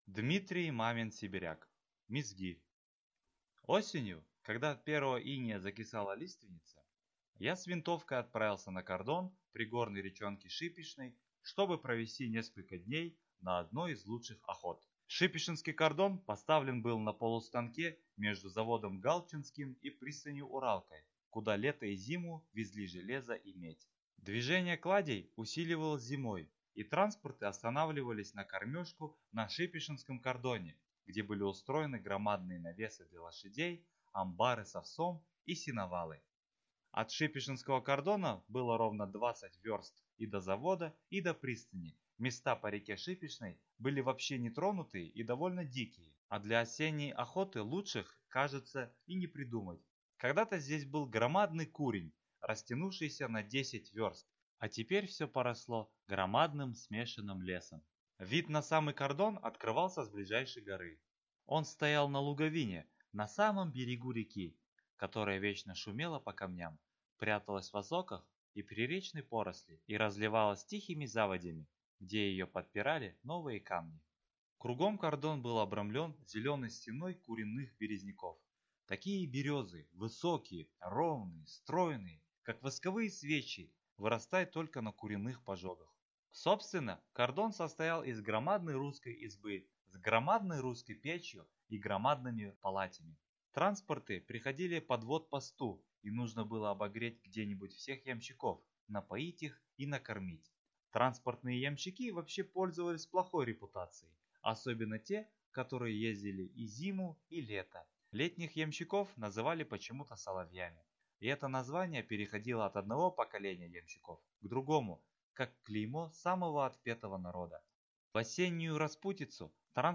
Аудиокнига Мизгирь | Библиотека аудиокниг